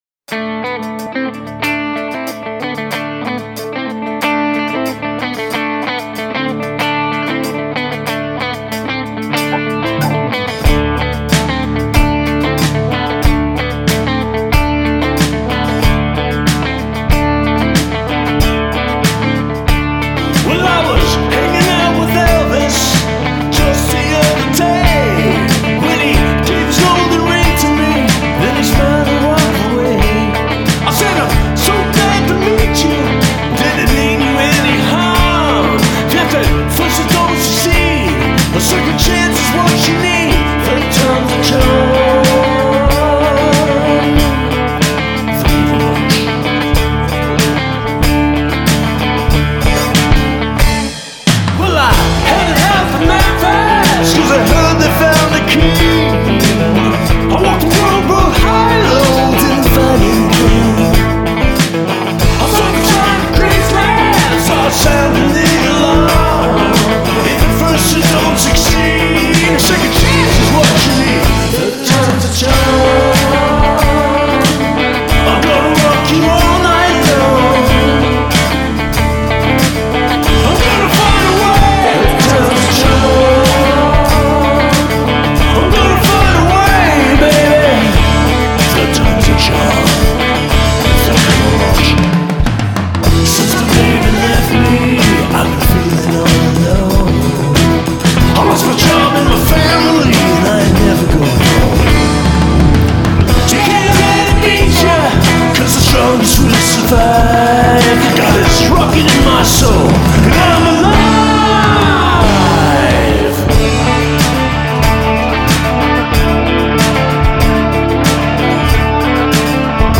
Version:  2024 REMIX OCT 2024
Country Rock standard
Harmonies